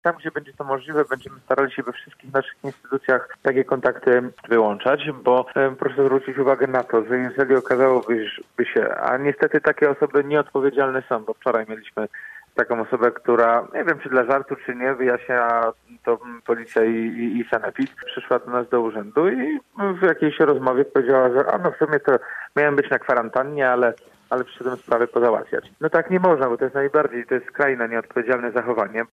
Działanie Urzędu Miasta w Gorzowie może być stopniowo ograniczane- mówił na naszej antenie prezydent Jacek Wójcicki. Urząd pracuje cały czas, ale tam, gdzie to możliwe ograniczana będzie bezpośrednia obsługa klientów.